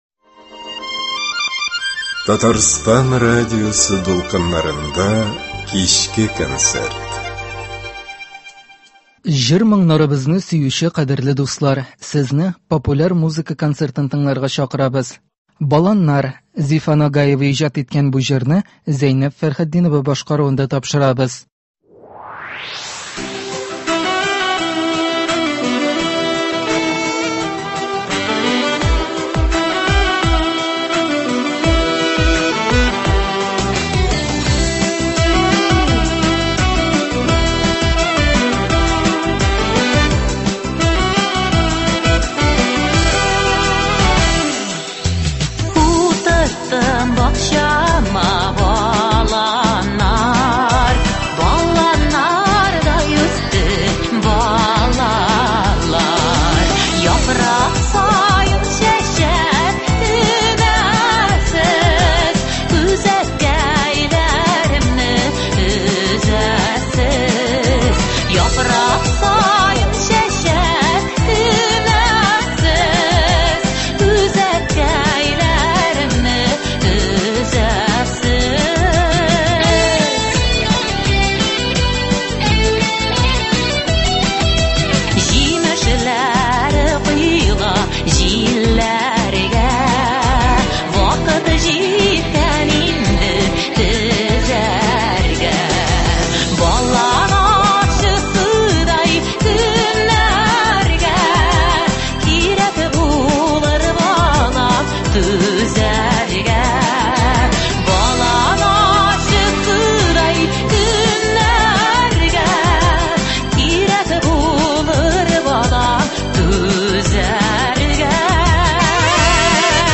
Популяр музыка концерты.